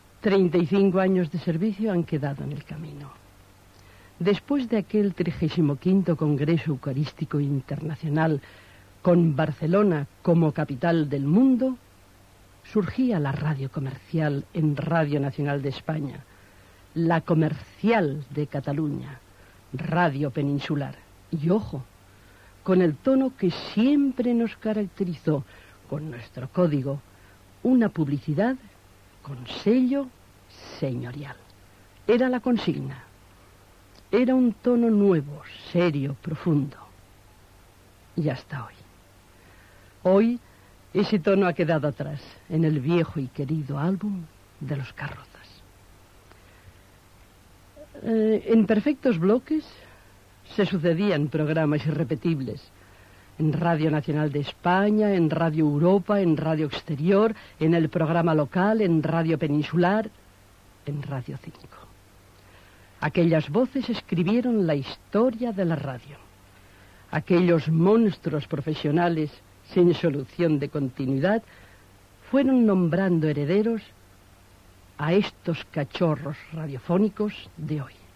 Tancament de l'emissora.
Presentador/a